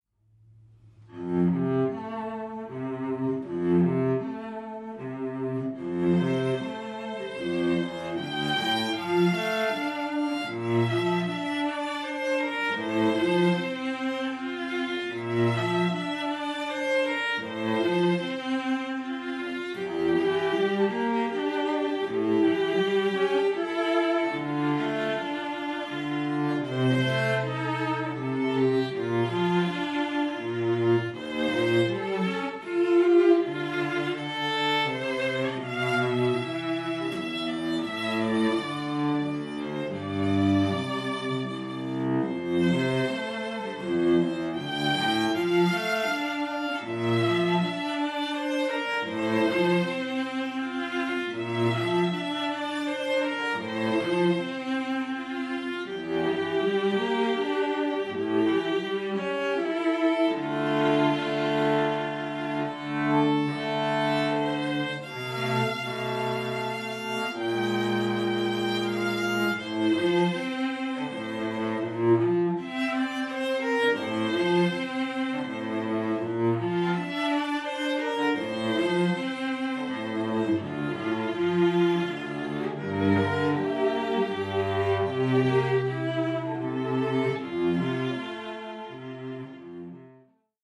Trio: Violin, Viola, & Cello – Contemporary